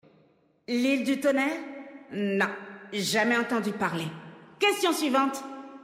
VF_TrollZandalari_Female_Blague_IleDuTonnerre.mp3